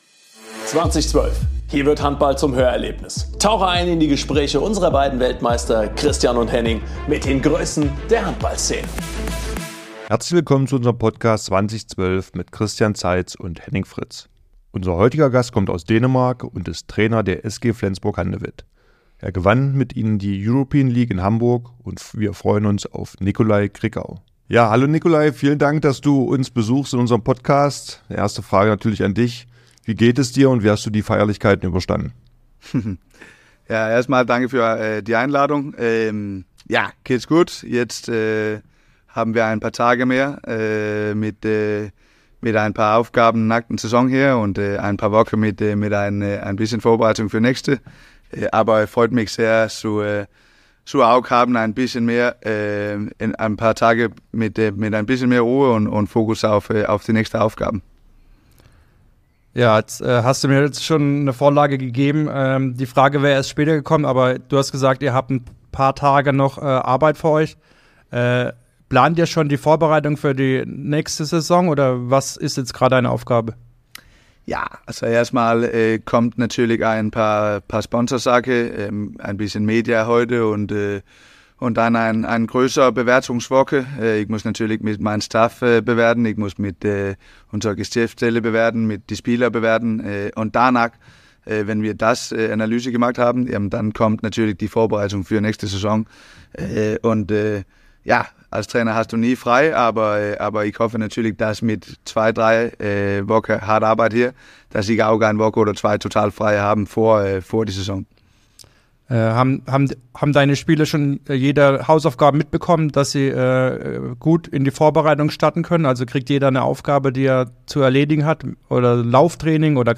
Herzlich willkommen zur 3. Staffel des Podcasts "20/12" mit den Gastgebern Christian Zeitz und Henning Fritz!